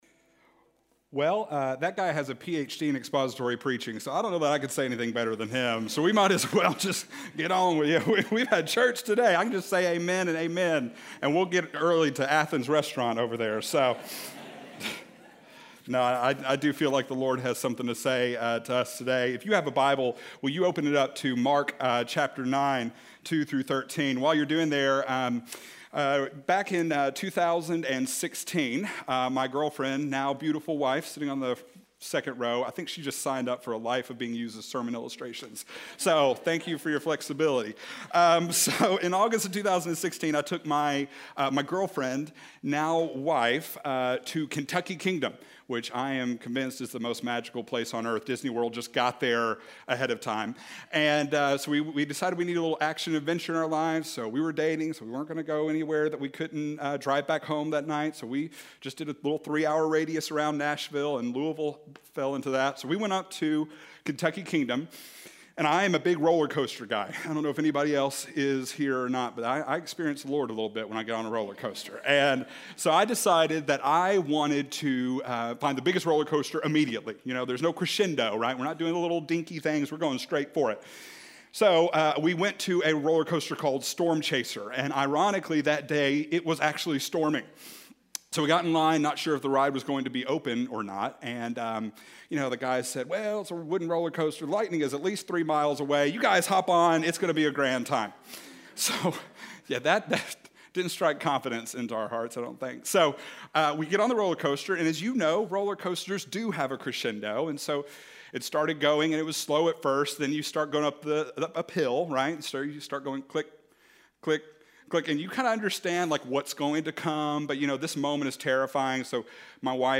Transfiguration - Sermon - Avenue South